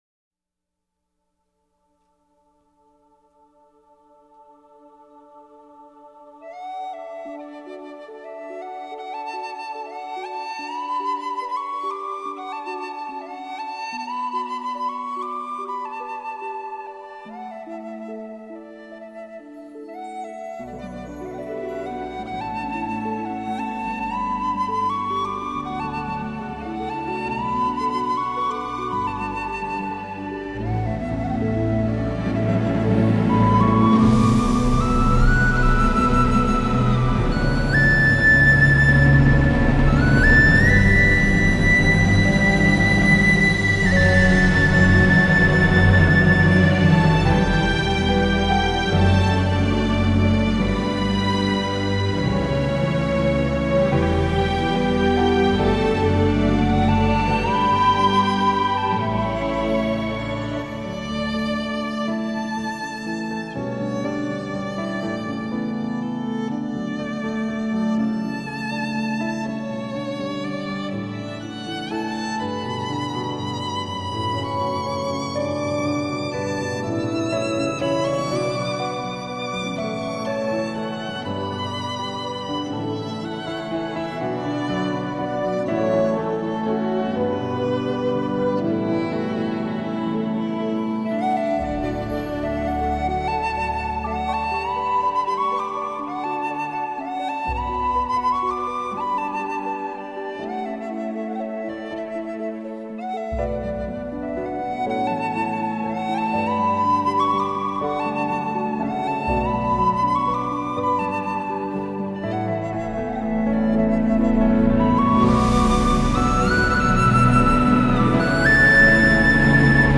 现自然，在他的音乐中，很少使用那些绚丽复杂的旋律，也很
少使用自然界的原声来强调主题，相反，那近乎对自然白描般